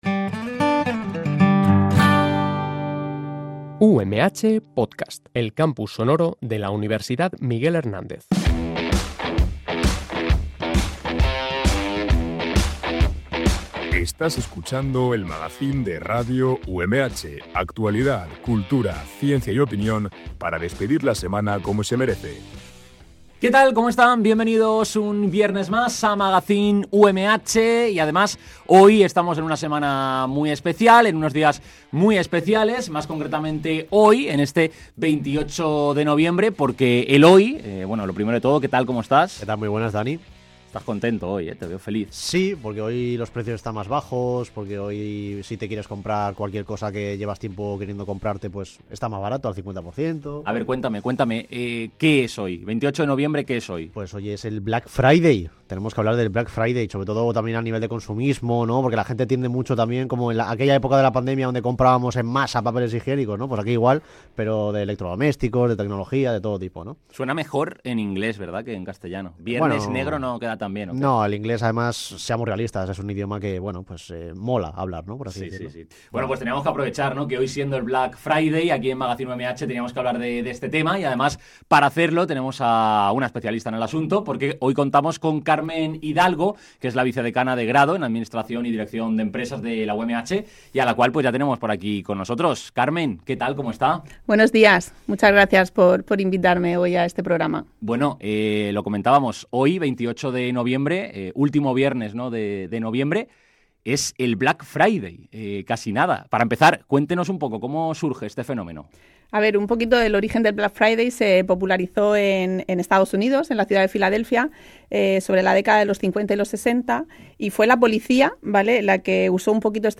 Además, salimos al campus de Orihuela-Salesas para conocer de primera mano qué opinan los estudiantes sobre esta jornada de descuentos.